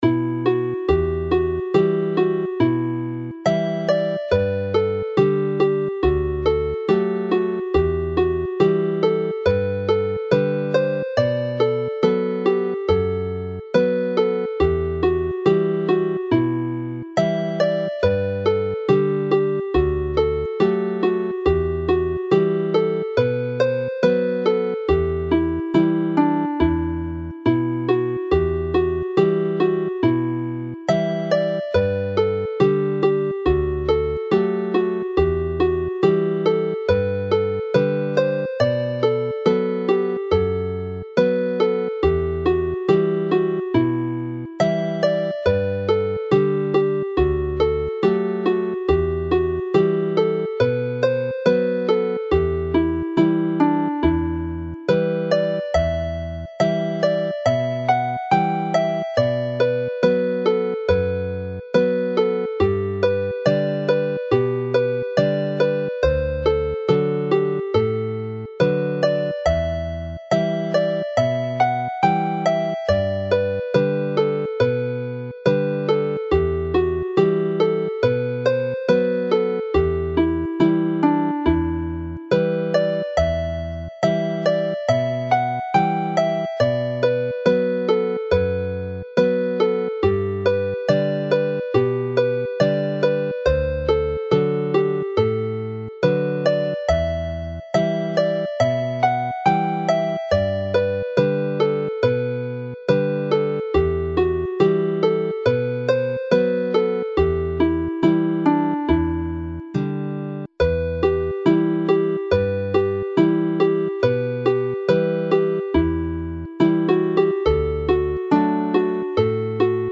Play the melody very slowly